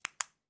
Звуки пишущей ручки
На этой странице собраны звуки пишущей ручки: от легкого постукивания по бумаге до равномерного скольжения стержня.